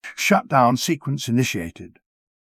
shut-down-sequence.wav